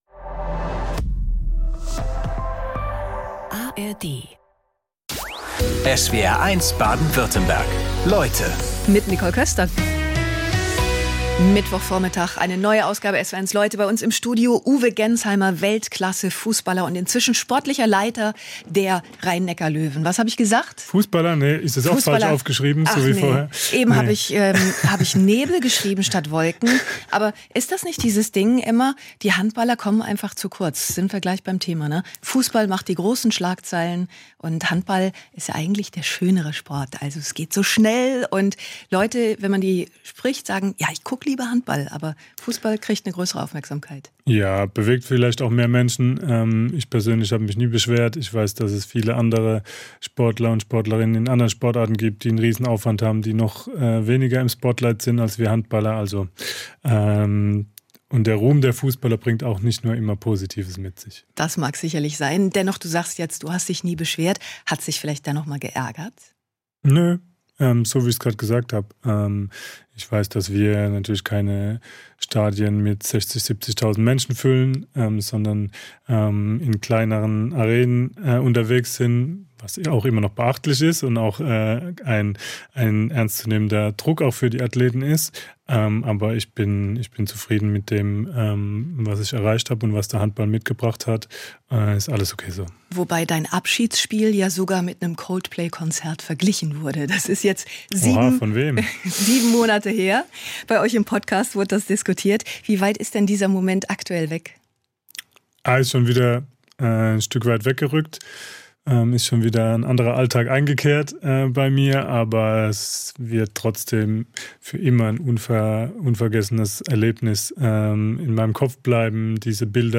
Talks mit besonderen Menschen und fesselnde Lebensgeschichten aus Politik, Wissenschaft, Sport oder Wirtschaft.